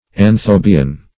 Search Result for " anthobian" : The Collaborative International Dictionary of English v.0.48: Anthobian \An*tho"bi*an\, n. [Gr.